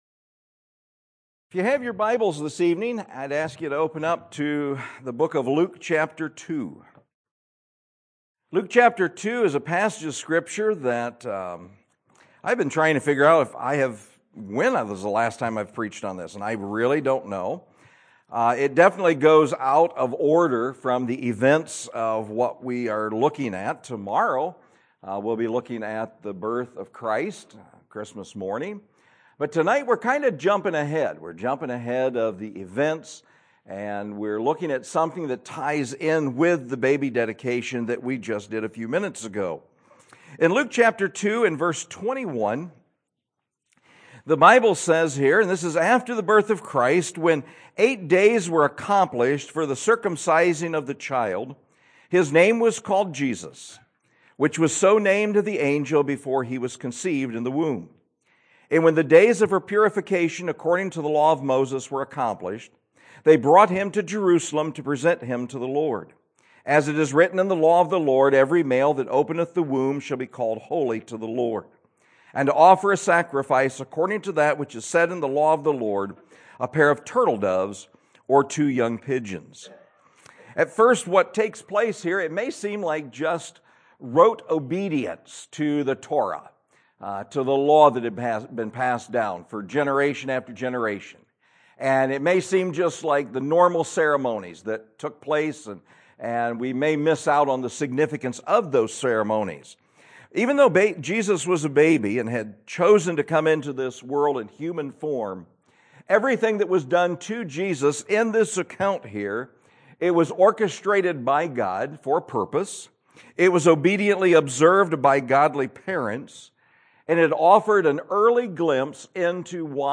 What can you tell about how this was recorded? Christmas Eve Service – 12/24/22